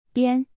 (biàn) — compile, edit